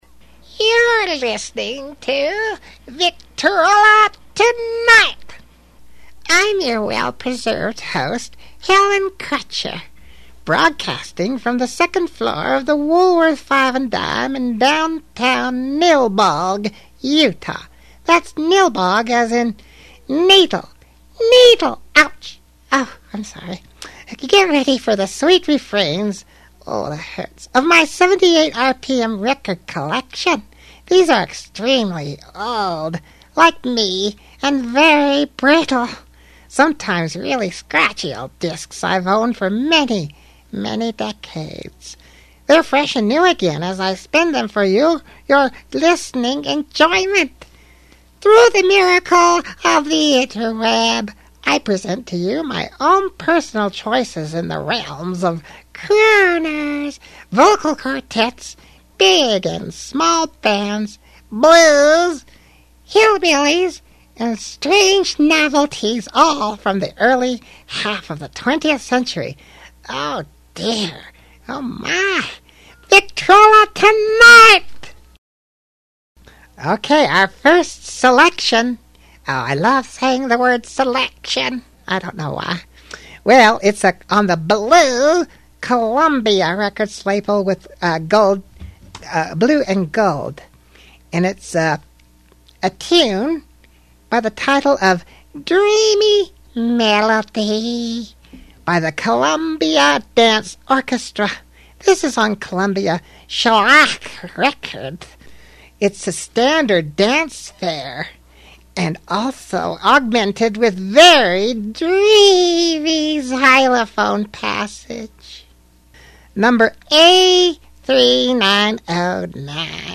I'm back with my latest 78rpm mp3 broadcast for your listening enjoyment!